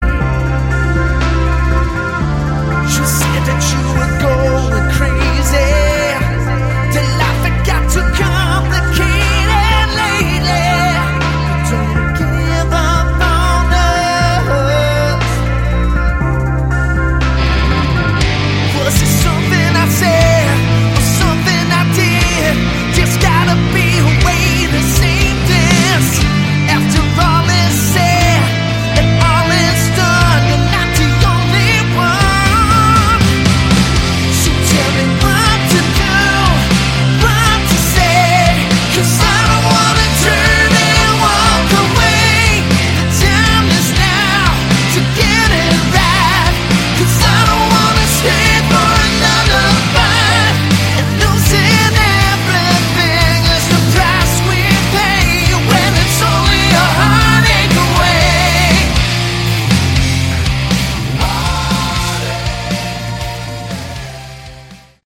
Category: Hard Rock
bass, keyboards
rhythm guitars, keyboards, backing vocals
lead and backing vocals
drums, backing vocals
lead guitar